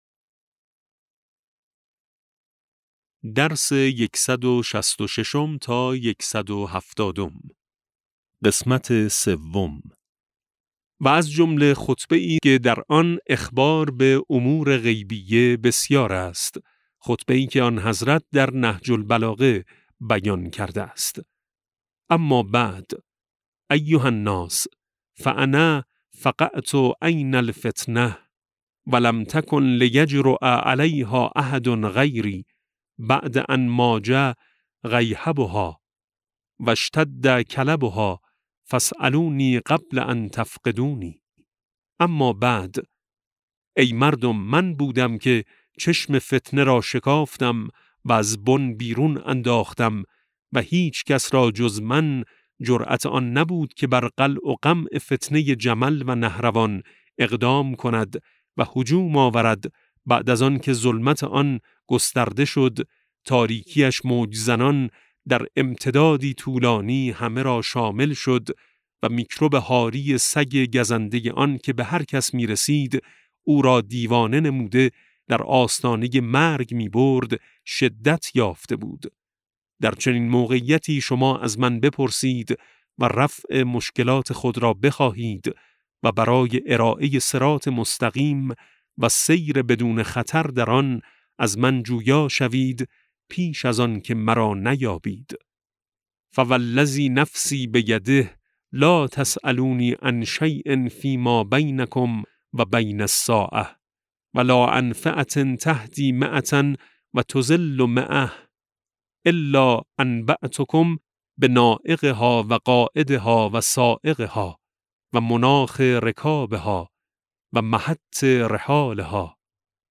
کتاب صوتی امام شناسی ج12 - جلسه3